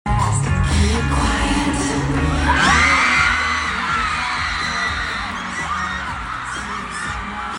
The crowd’s reaction 😭😍 This sound effects free download